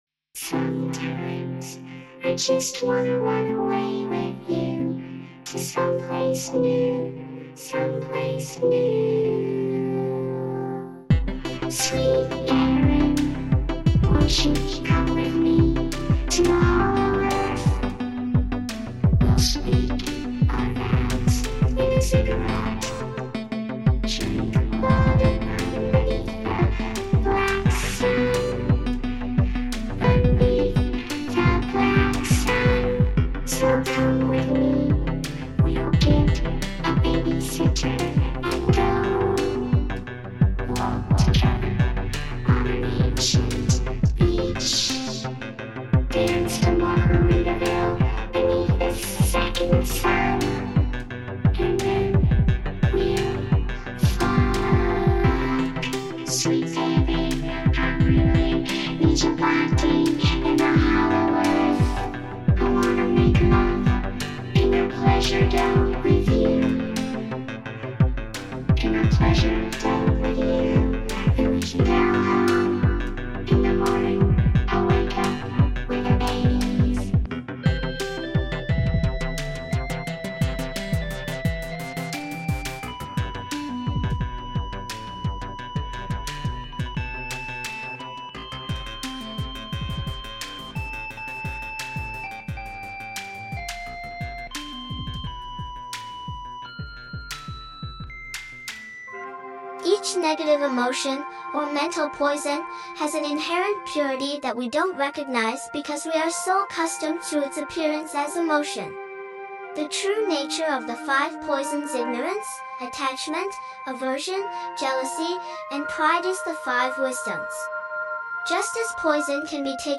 Comedy Interviews, Religion & Spirituality, Society & Culture, Philosophy, Comedy